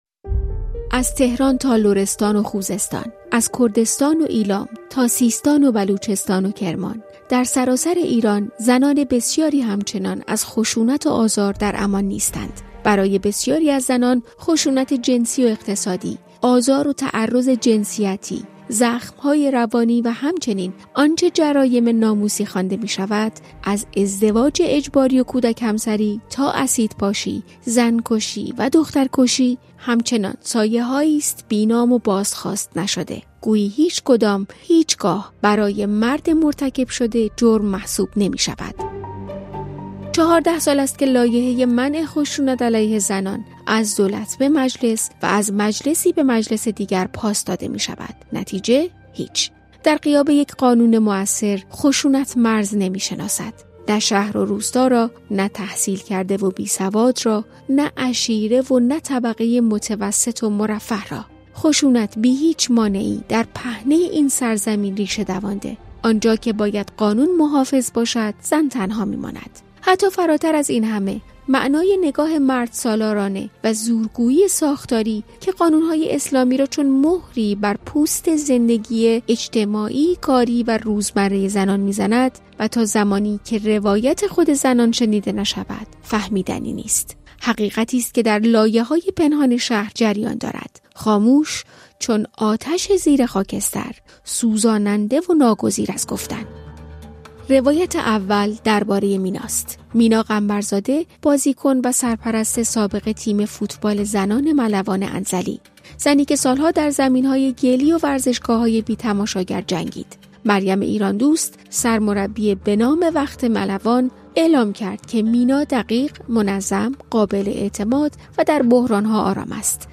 هفت روایت از انواع خشونت علیه زنان بشنوید. در این مستند، صدای برخی از راویان، به‌دلیل مسائل امنیتی تغییر کرده است.